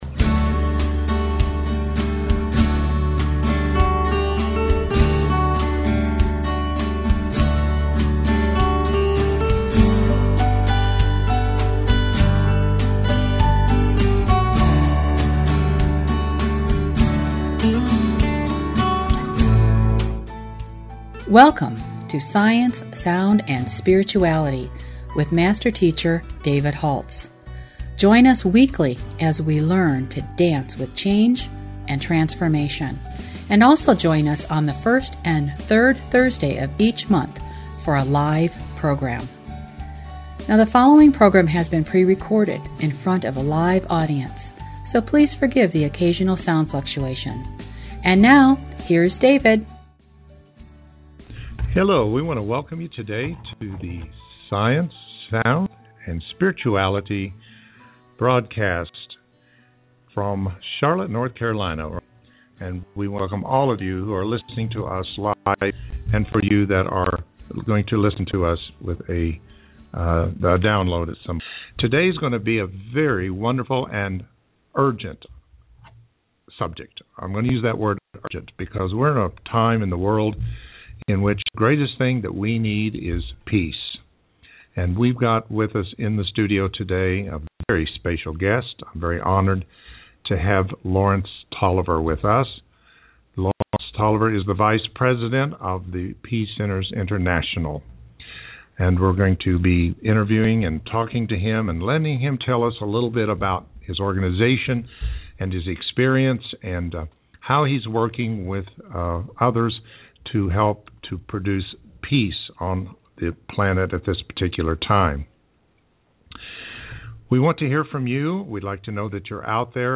Talk Show Episode
Interview